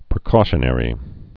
(prĭ-kôshə-nĕrē) also pre·cau·tion·al (-shə-nəl)